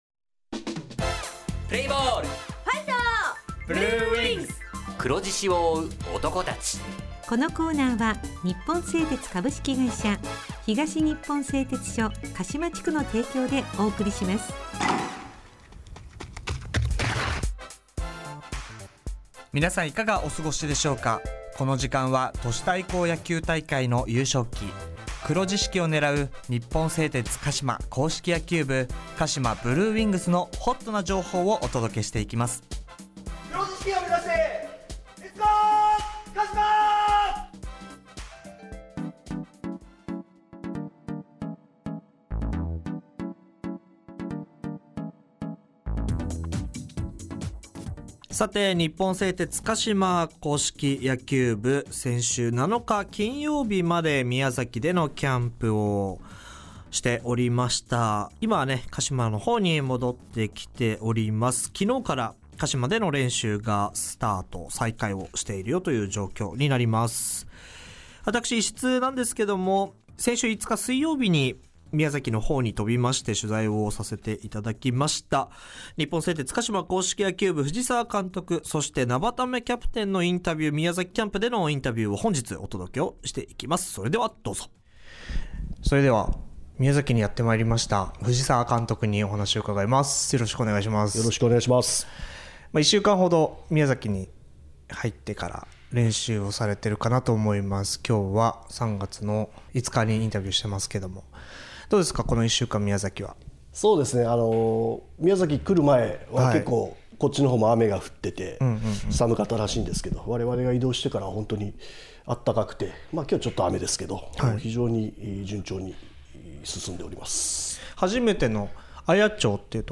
地元ＦＭ放送局「エフエムかしま」にて鹿島硬式野球部の番組放送しています。